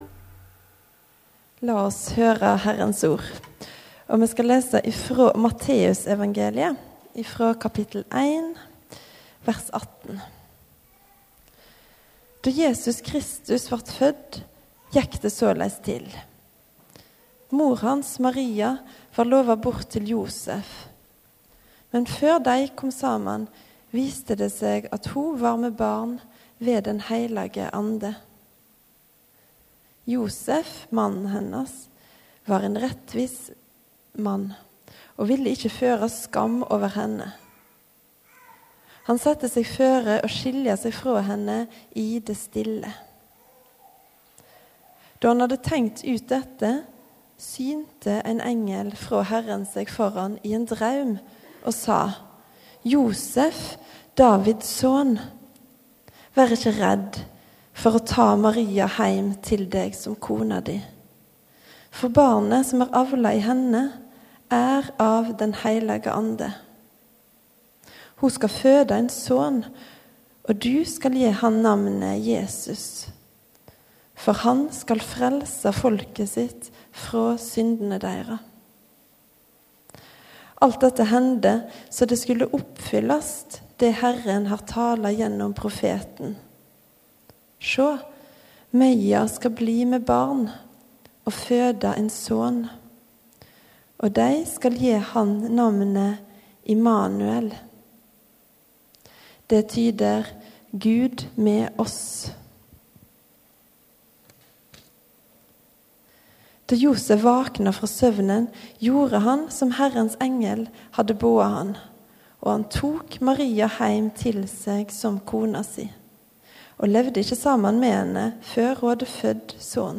Gudstjeneste kveld, 12. des. 3. søndag i advent, -Josef | Storsalen